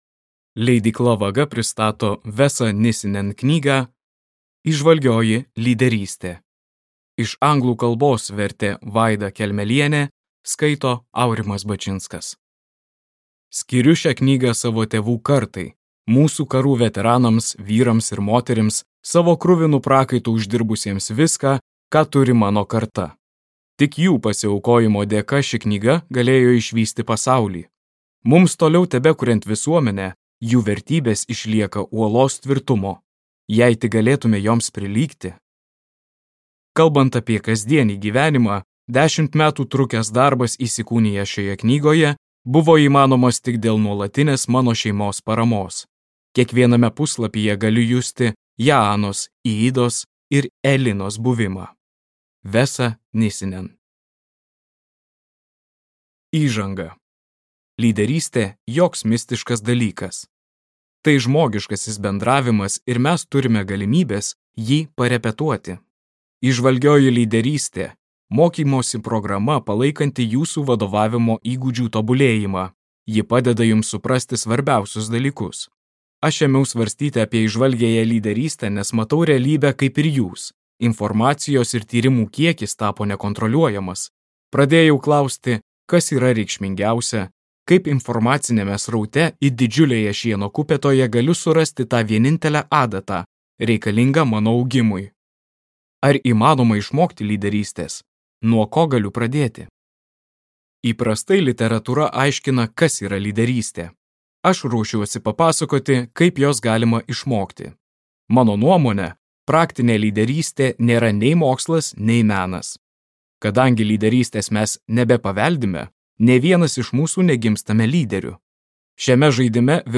Įžvalgioji lyderystė | Audioknygos | baltos lankos